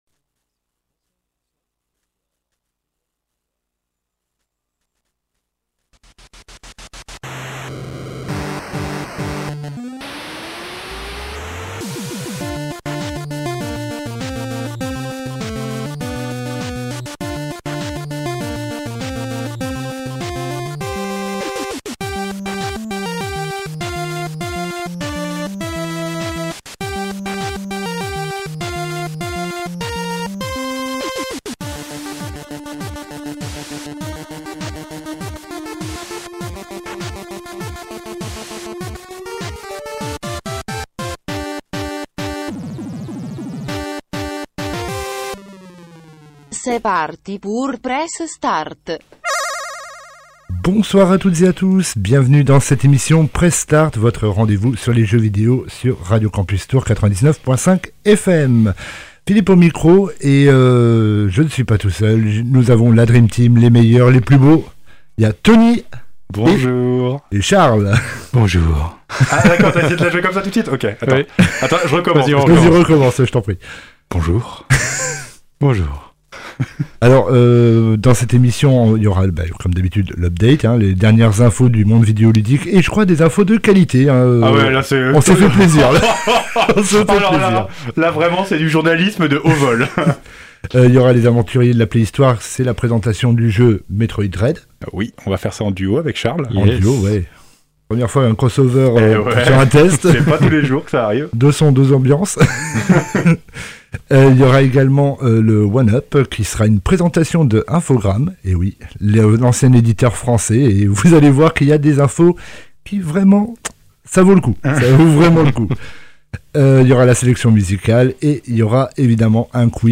Press Start, c’est votre émission jeux vidéo, chaque (quasiment) mercredi de 19h à 20h sur Radio Campus Tours!